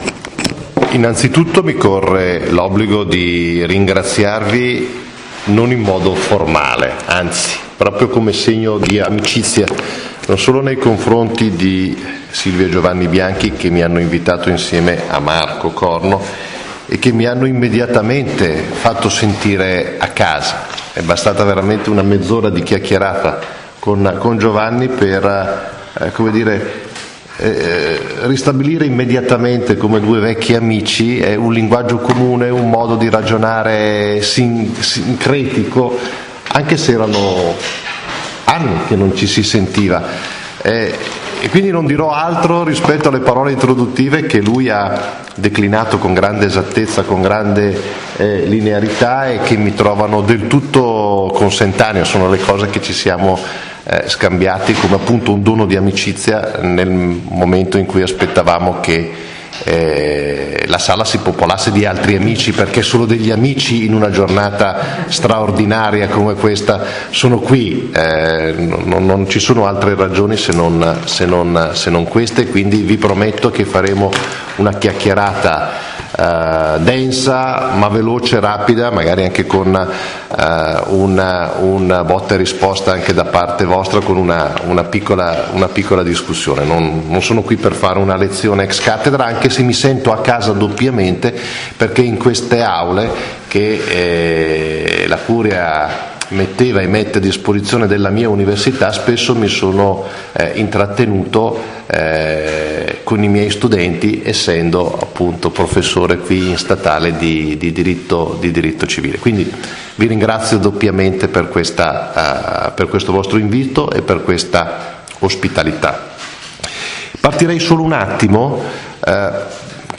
I diritti in internet sono il tema affrontato da Gregorio Gitti nell'ottava lezione del Corso di formazione alla politica 2016/2017 dei Circoli Dossetti.